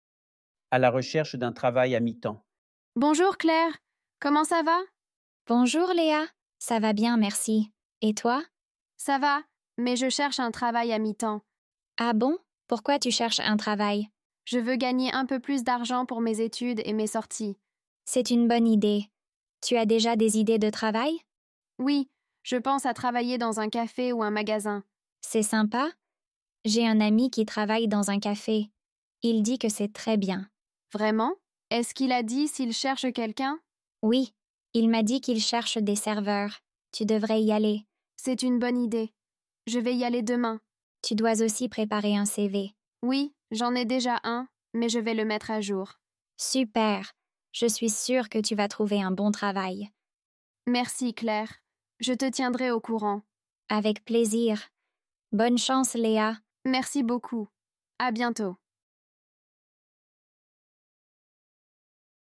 Dialogue – Chercher un travail à mi-temps (A2)